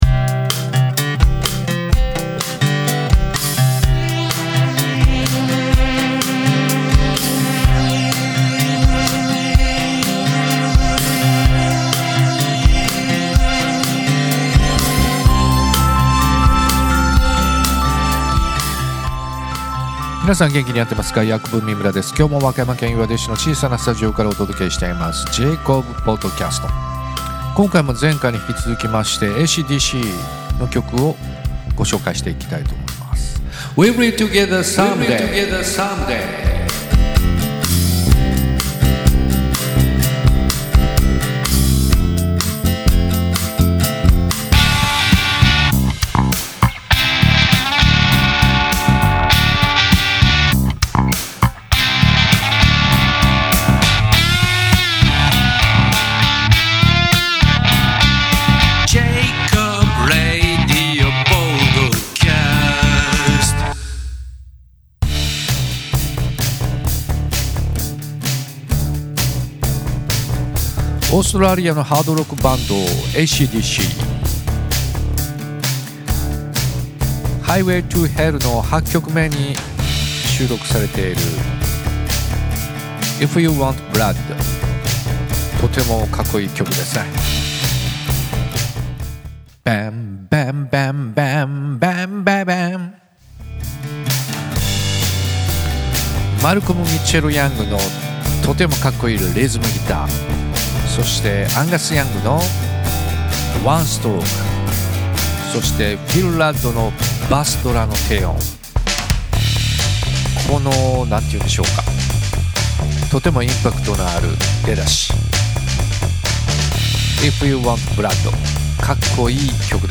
・エレキギター
・キーボード（keys、ベース）
・ボーカル
・ドラム（GarageBand）